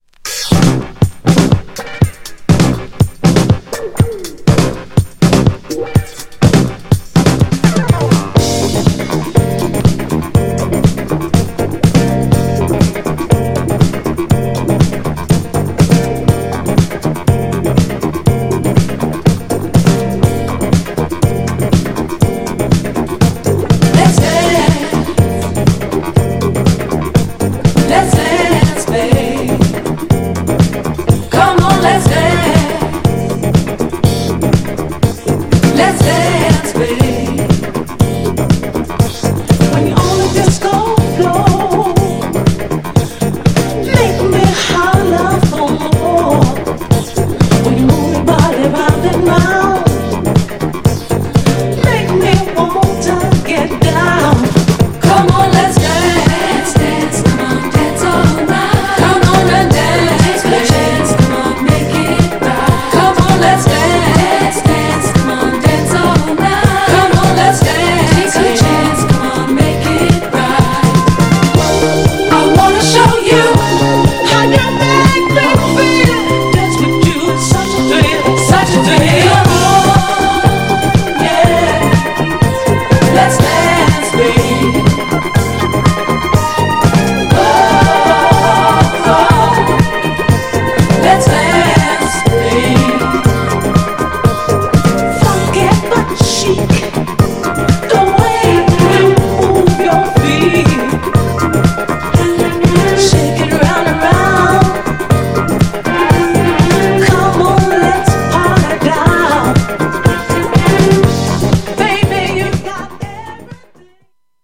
アフロとコズミックなサウンドがミックスされたような独特のサウンド!!
GENRE Dance Classic
BPM 111〜115BPM